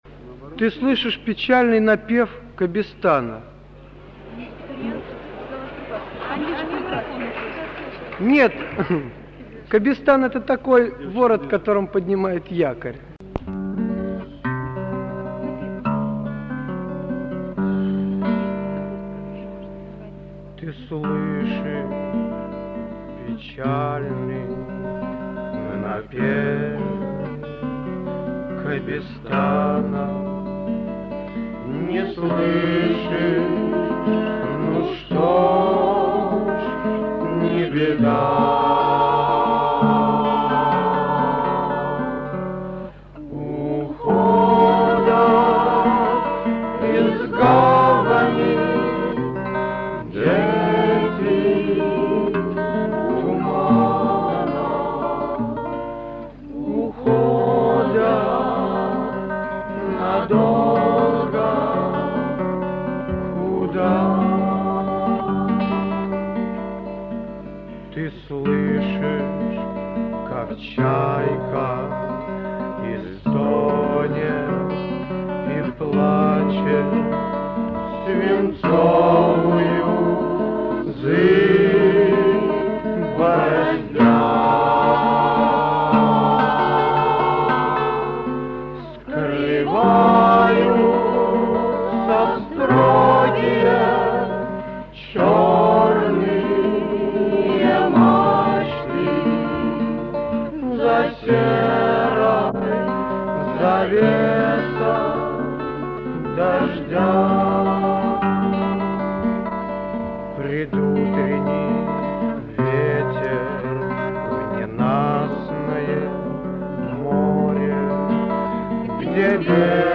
Аудиозаписи Третьего Московского конкурса студенческой песни
ДК МЭИ. 7 декабря 1961 года.
под гитарное сопровождение